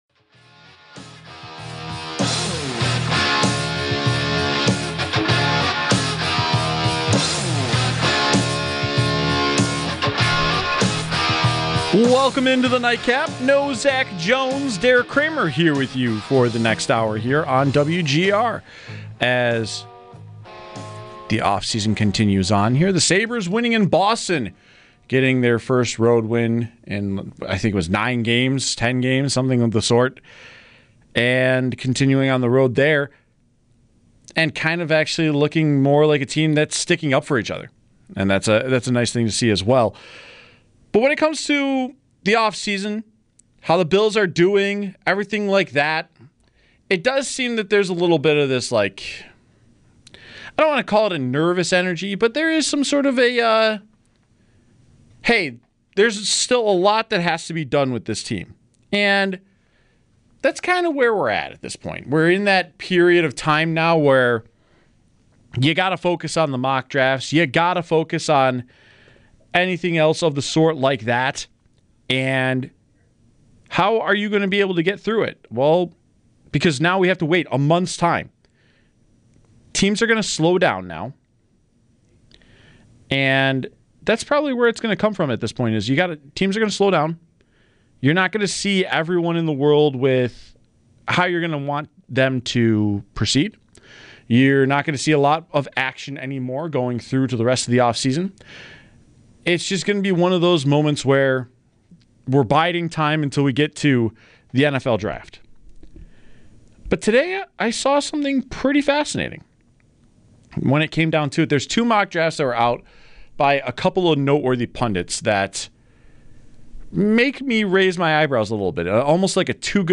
Hangout with Night Cap in the evenings, 7-9pm, where we will bring you the best interviews and bits that WGR has to offer throughout the day along with opinions on pressing issues in Buffalo sports.